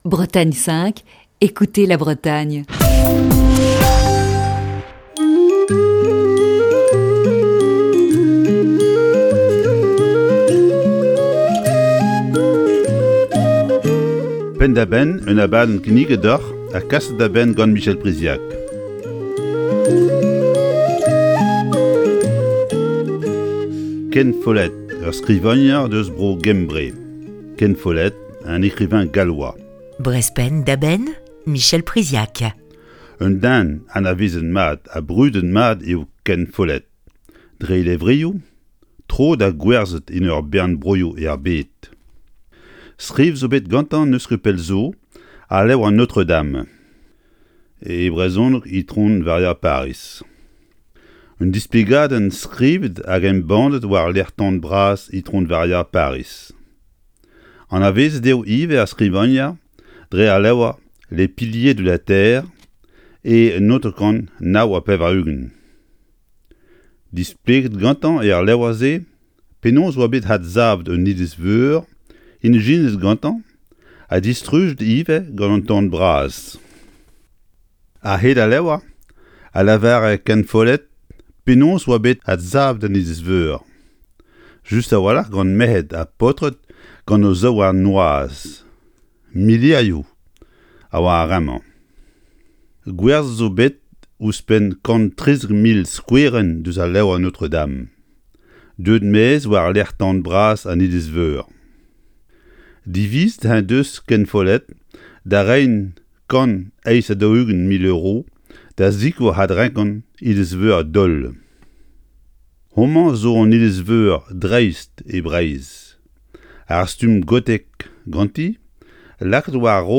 Chronique du 2 avril 2021.